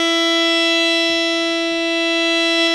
52-key14-harm-e4.wav